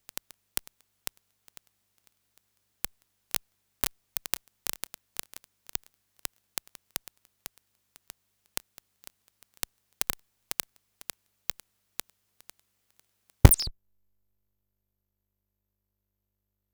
Lexicon MX-400 V1.2 Треск в каналах
Аналоговый выход канал А(Front - Left-Right) в режиме Stereo и Surround появился шум-щелчки, попадая в эффект умножается создается звучание общего невнятного шума, прилагаю семплы данной проблемы - Audio 01_06.wav Audio 01_07.wav эти самые щелчки...